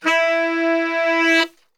E 2 SAXSWL.wav